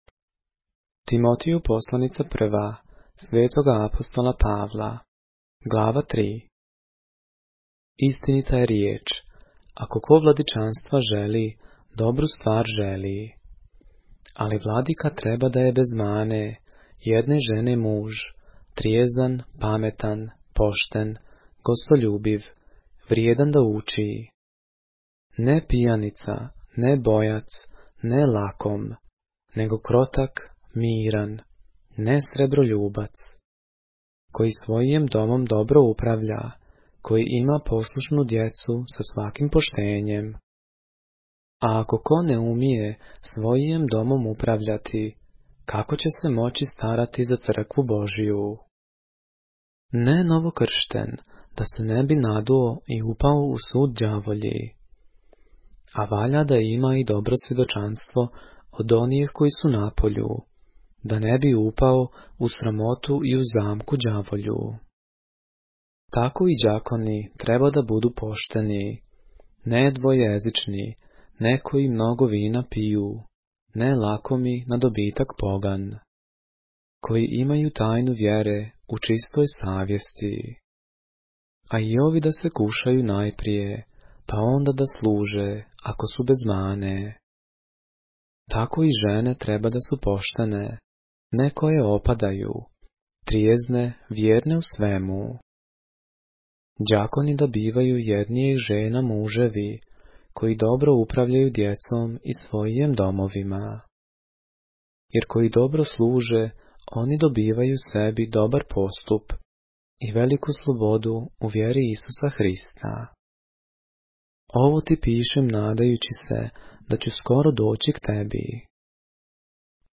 поглавље српске Библије - са аудио нарације - 1 Timothy, chapter 3 of the Holy Bible in the Serbian language